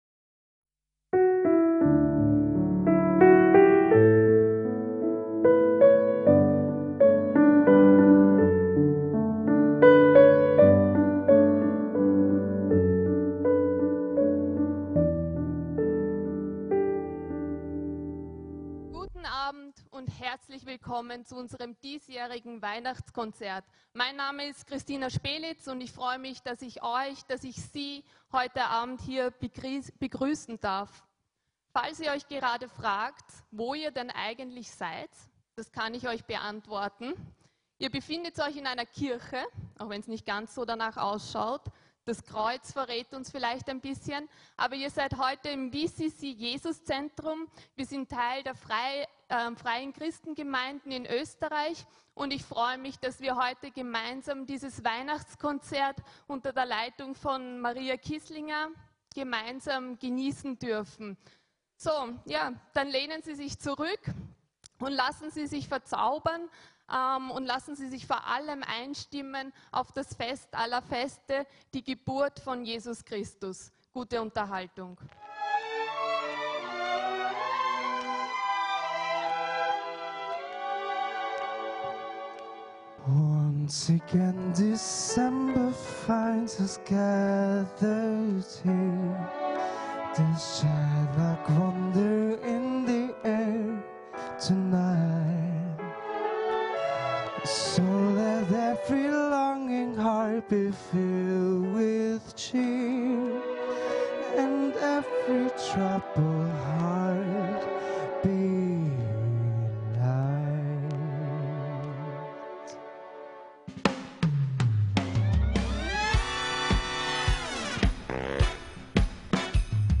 WEIHNACHTSKONZERT 2018